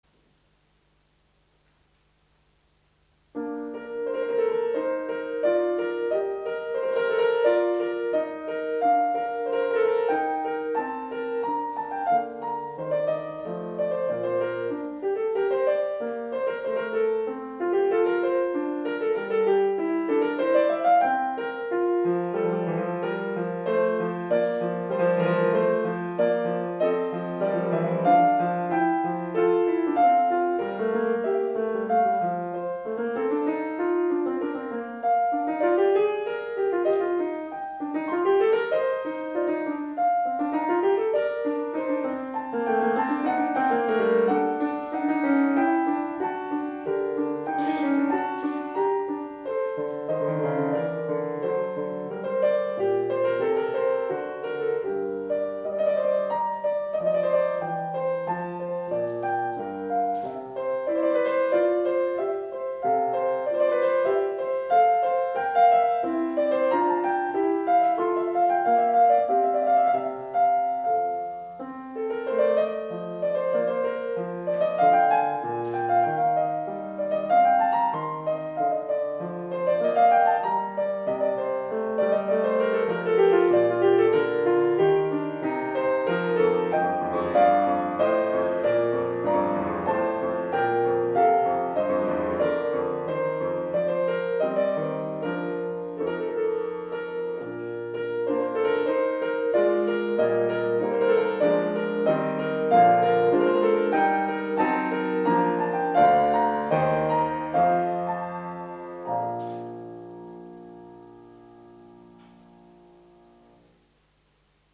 ピアノ生演奏ファイル(1)
・mp3ファイルは、主に家のピアでの演奏した生演奏です。
使用楽器は、主にドイツ製楽器ザウターモデル160、YAMAHA G5（2005年末オーバーホール済み）です。
音量が小さい演奏もありますので、パソコンの音量で調整してください。
第1曲 プレリュード mp3  2015年発表会での講師演奏新曲